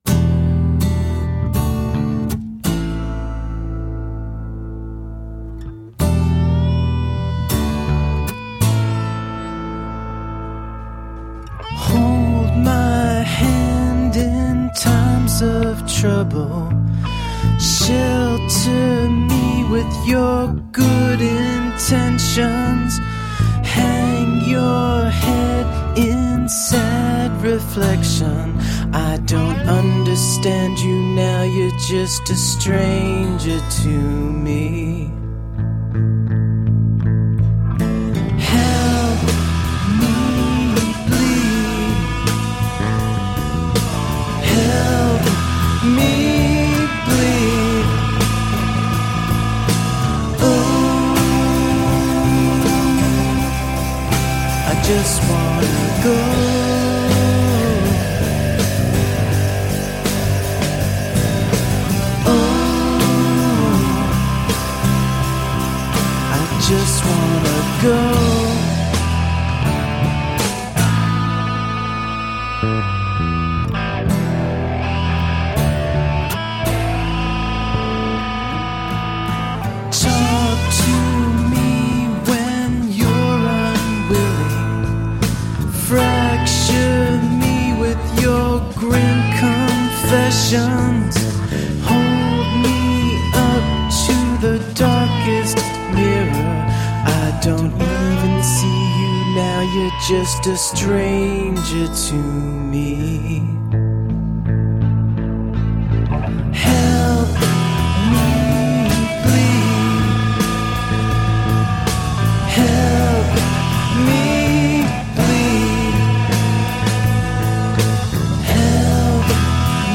A catchy brand of alternative roots rock.
Tagged as: Alt Rock, Folk-Rock, Folk